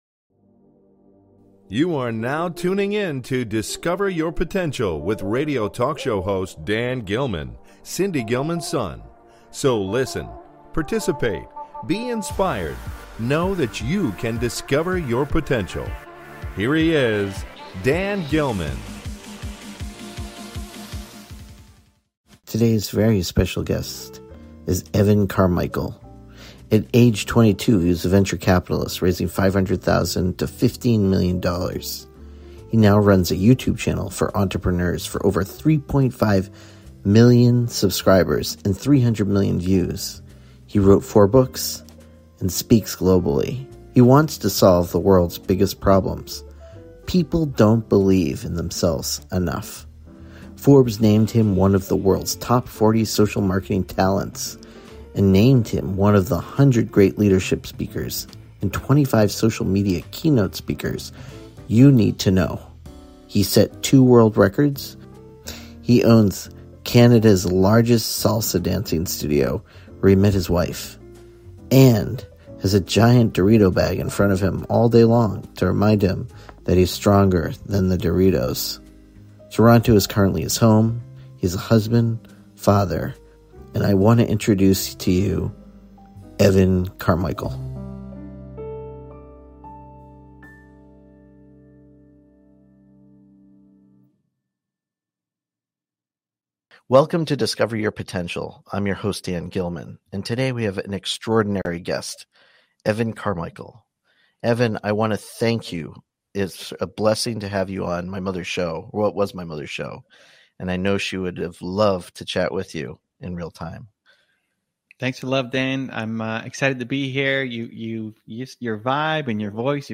Talk Show Episode
Guest, Evan Carmichael